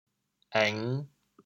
潮州 êng5 zêng3 潮阳 êng5 zêng3 潮州 0 1 潮阳 0 1
êng5.mp3